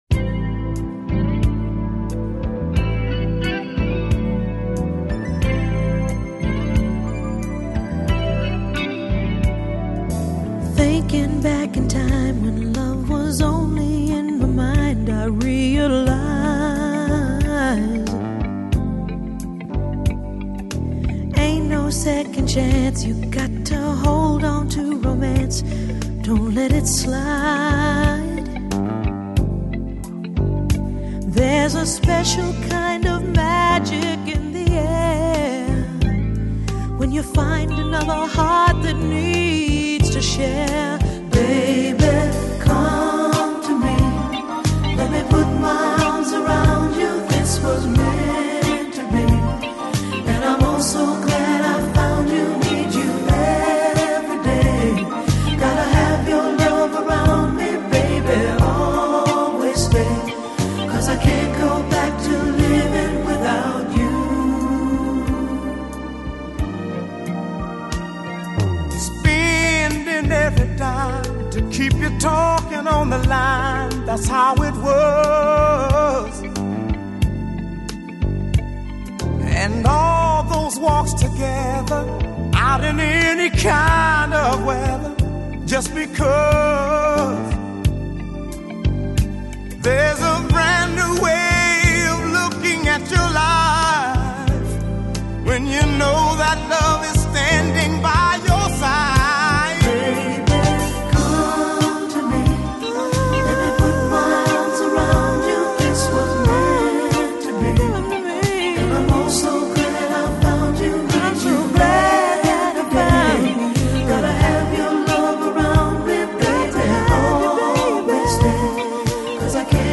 Жанр: Vocal Jazz, Soul, R&B